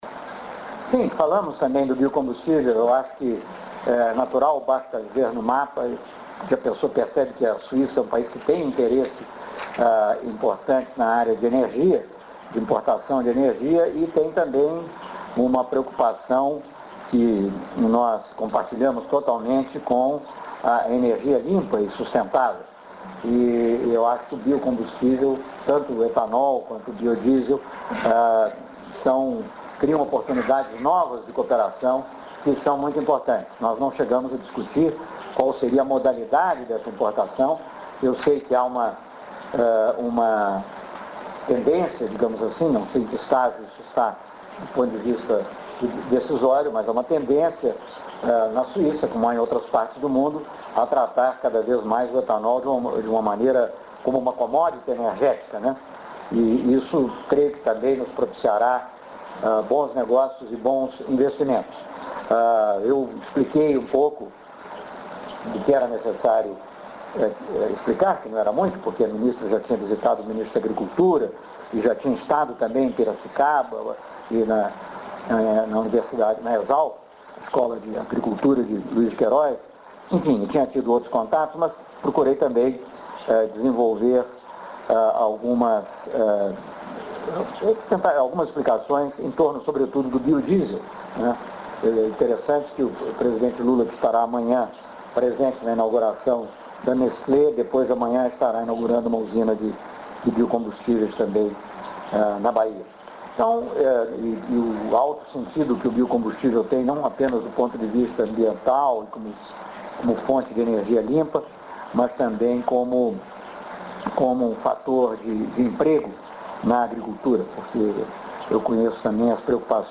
Celso Amorim explica o que discutiu com a ministra suíça da Economia, Doris Leuthard, interessa em biocombustível.